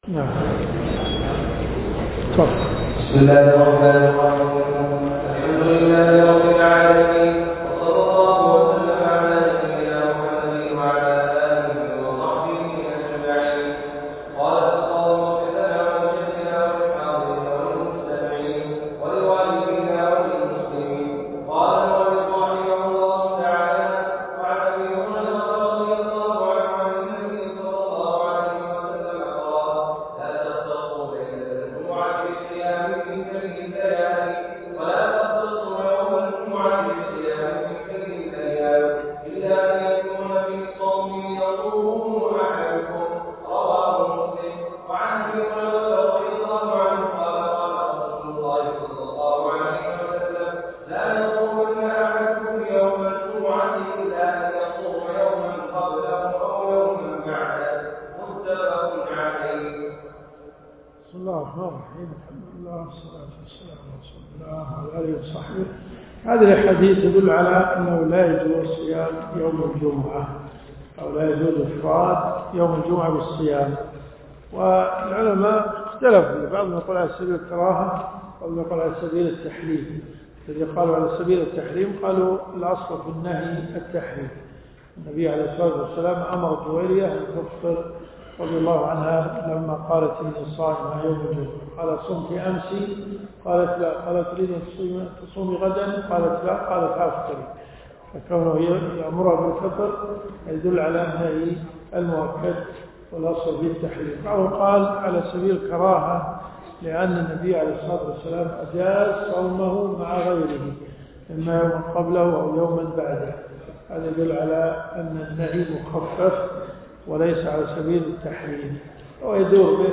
الدروس الشرعية
الرياض . حي العارض . جامع عبدالله بن ناصر المهيني . 1445 + 1446 .